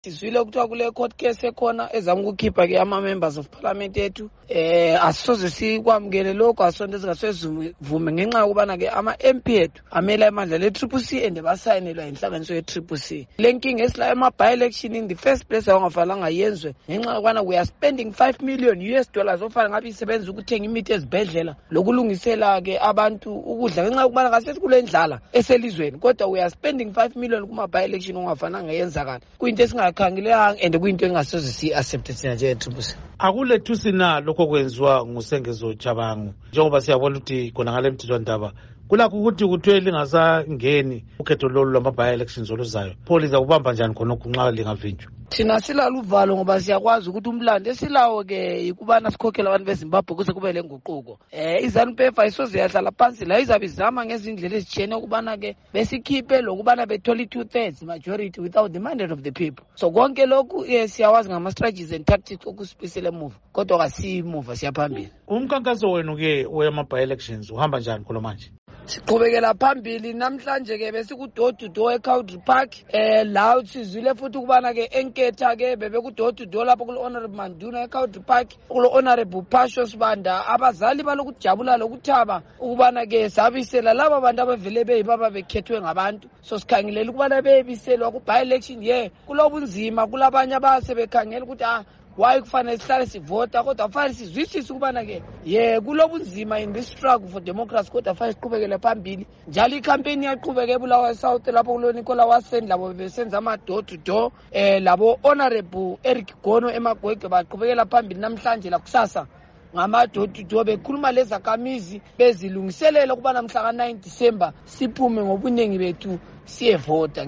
Igxoxo Esiyenze LoMnu. Gift Ostallos Siziva